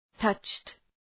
{tʌtʃt}